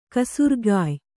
♪ kasurgāy